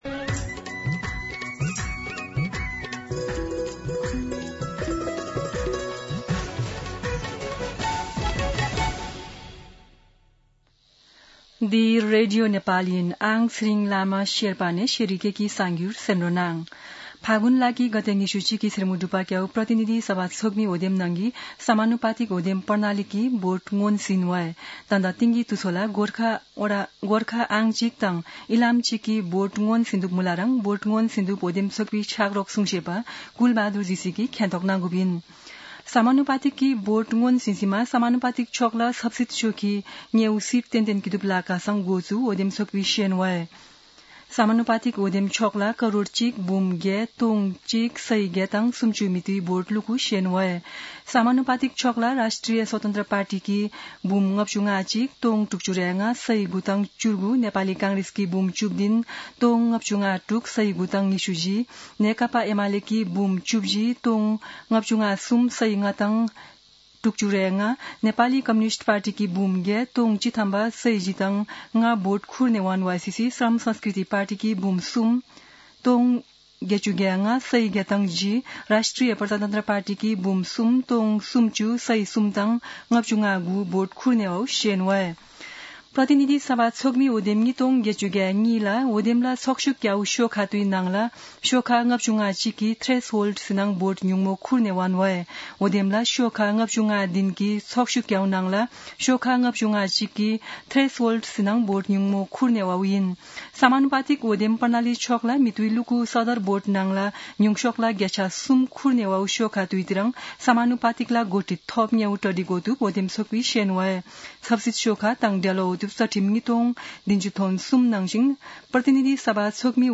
शेर्पा भाषाको समाचार : २७ फागुन , २०८२
Sherpa-News-27.mp3